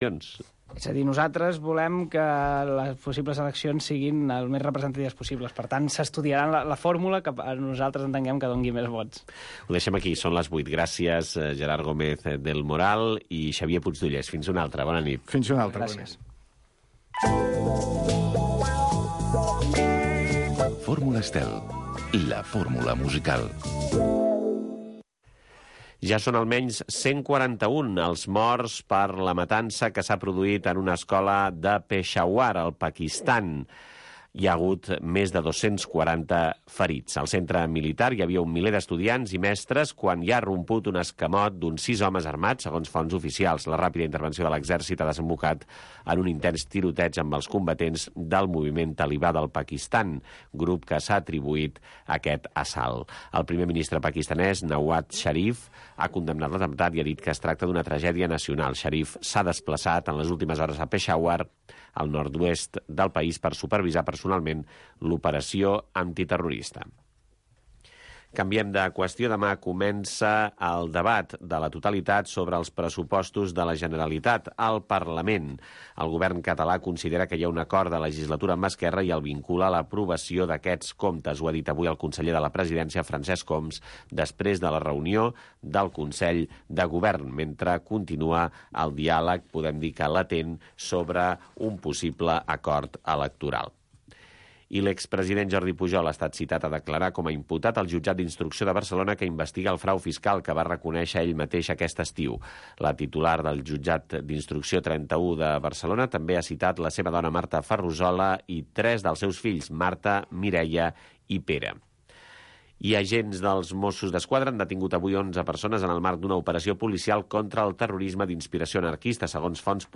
Un programa amb entrevistes i tertúlia sobre la família amb clau de valors humans, produït pel l'associació FERT.